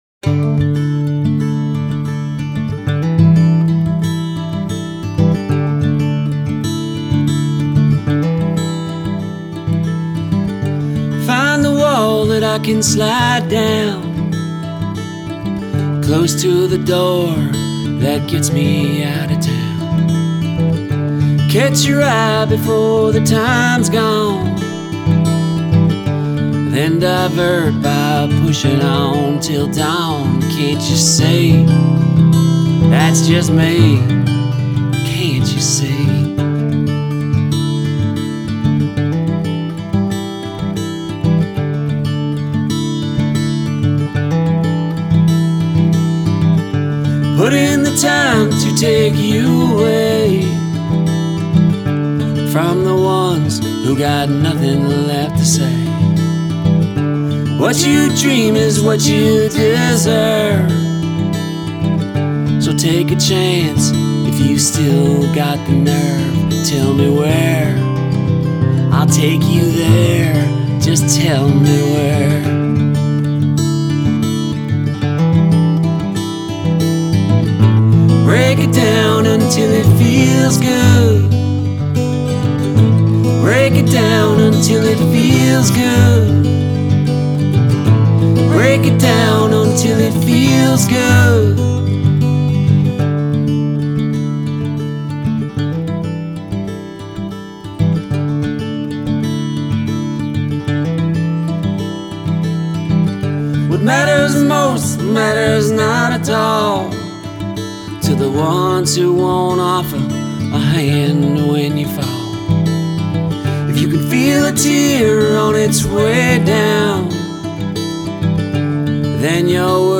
vocals, guitar
mandolin, harmonica, background vocals
recorded in quarantine from our respective bunkers